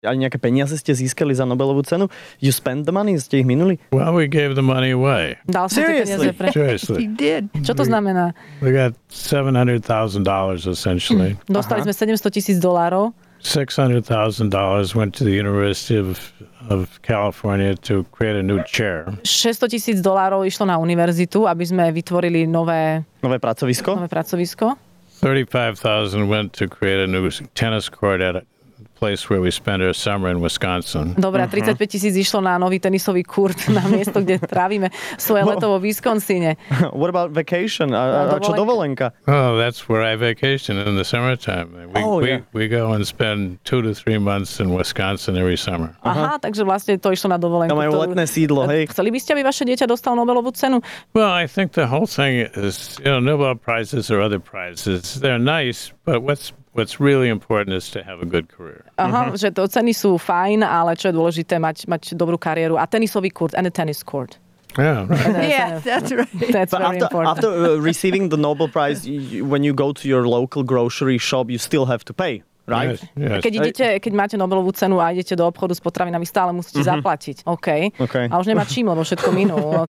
Ranná šou s Adelu a Sajfom - hosť: prof. Oliver Williamson